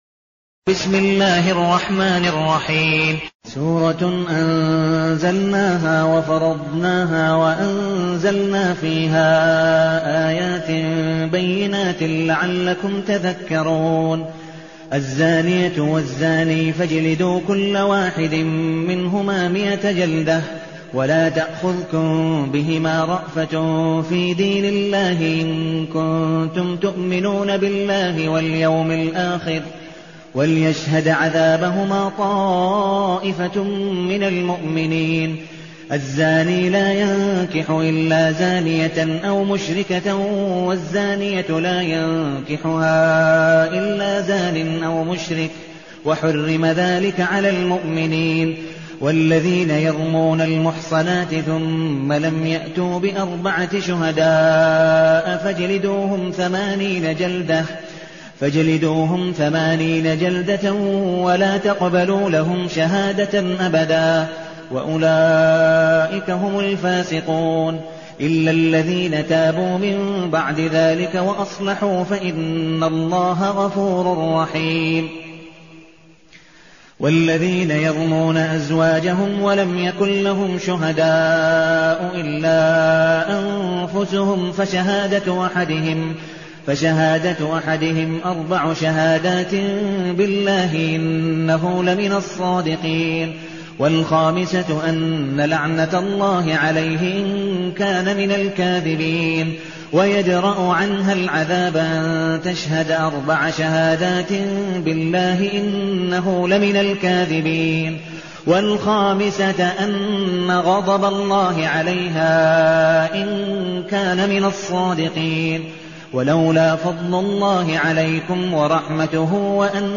المكان: المسجد النبوي الشيخ: عبدالودود بن مقبول حنيف عبدالودود بن مقبول حنيف النور